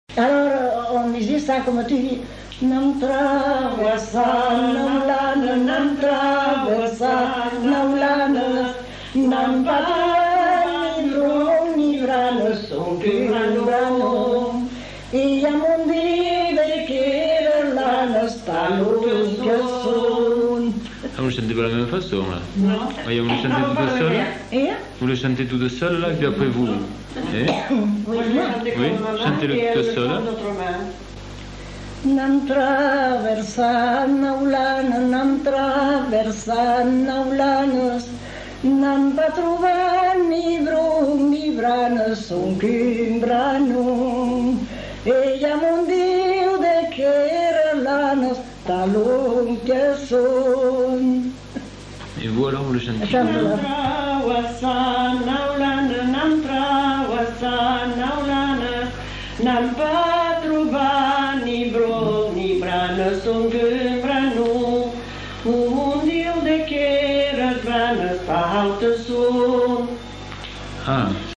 Lieu : Mont-de-Marsan
Genre : chant
Effectif : 2
Type de voix : voix de femme
Production du son : chanté
Notes consultables : Chantée par deux femmes puis chacune chante sa version.